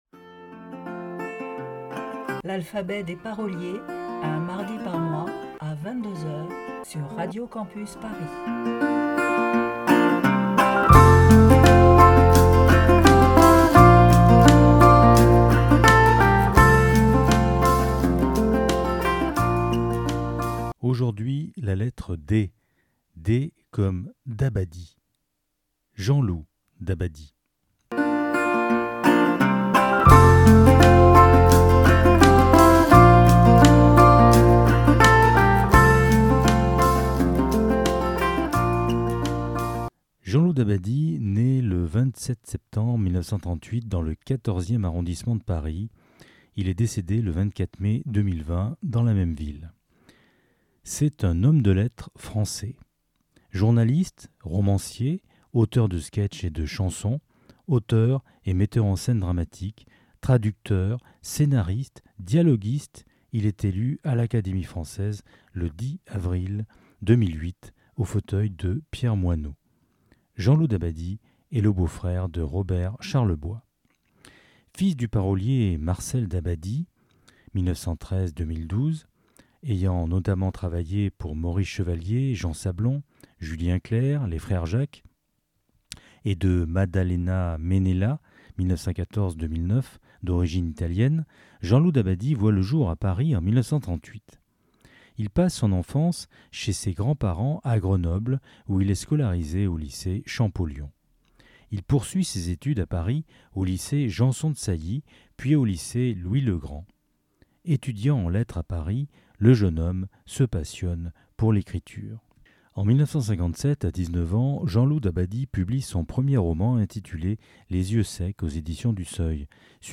Magazine Pop & Rock